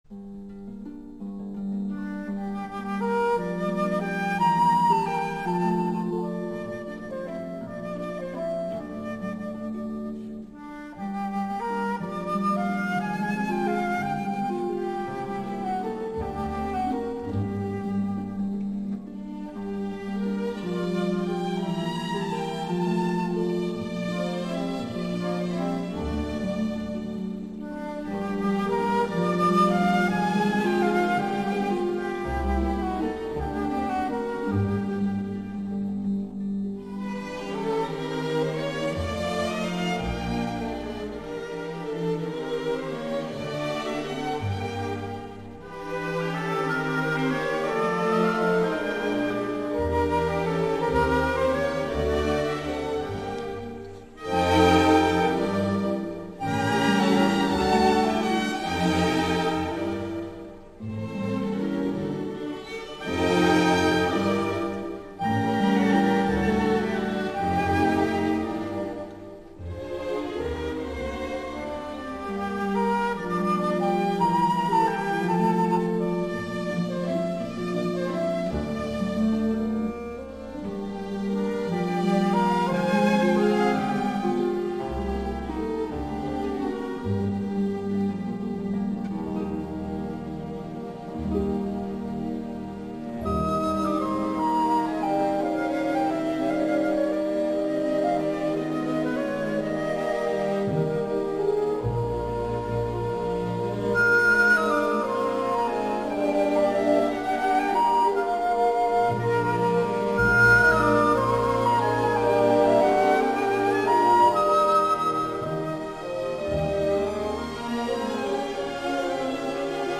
长笛Flute:
乐曲为三部曲式，g小调，小行板，6/8拍子。在分解和弦的伴奏音型衬托下，长笛轻轻地奏出了第一部分主题。
中间部转至降E大调，乐队在高音区柔和地奏出中间部主题，同时长笛采用对位旋律与主题形成浑然一体的交织。